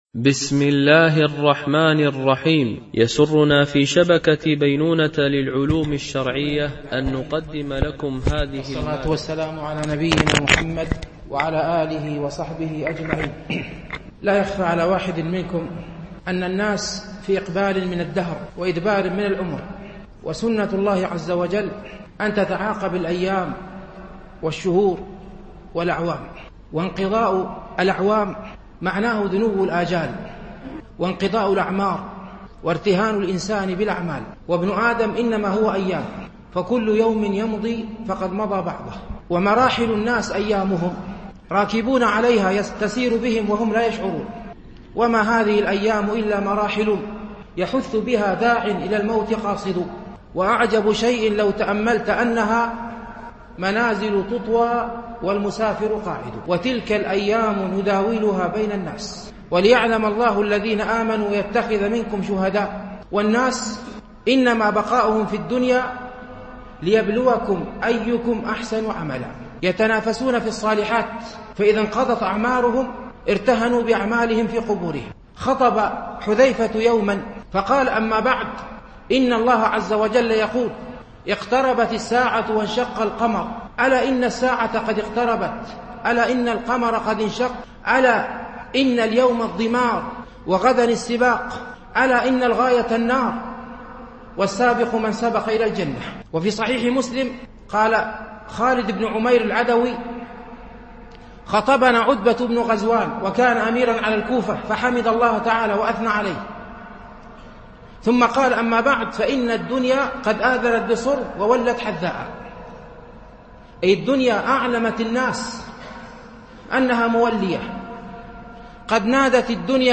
موعظة دينية